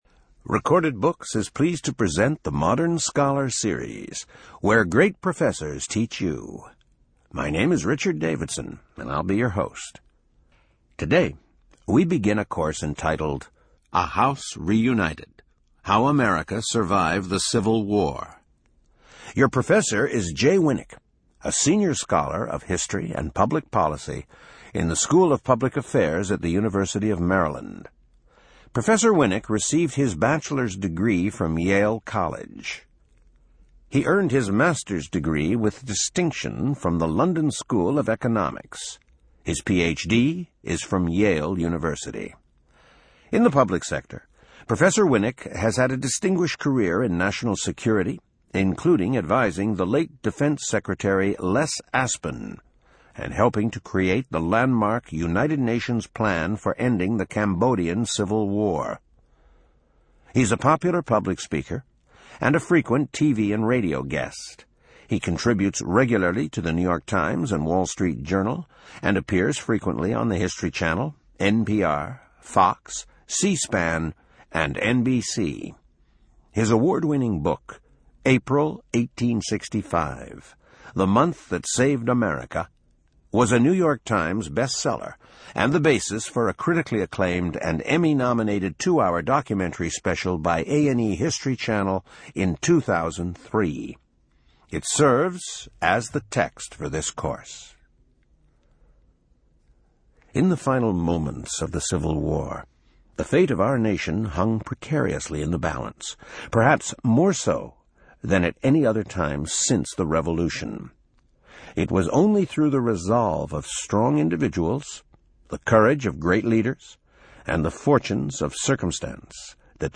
In this introductory lecture, Professor Jay Winik traces what led America into the grip of Civil War, and begins a series on how the nation survived.